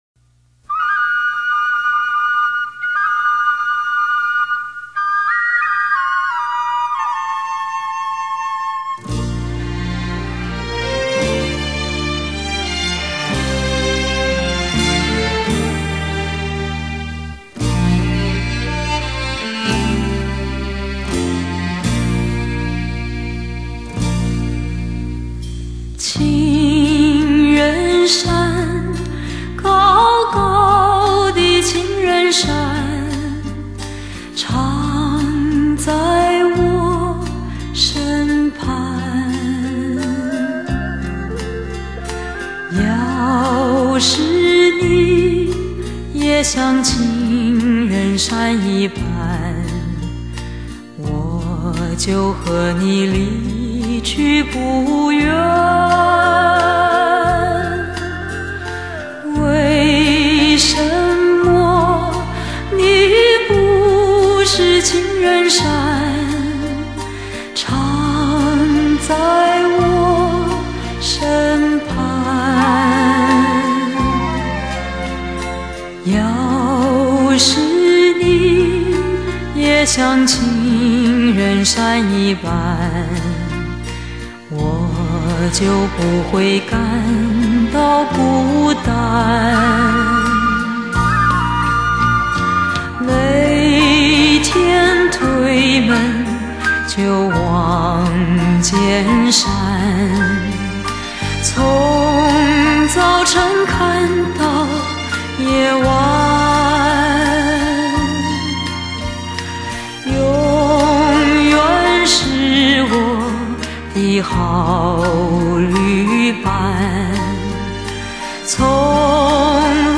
时光流逝，她的歌声明净醇厚不变；爱情老了，她的微笑沉静温暖不老……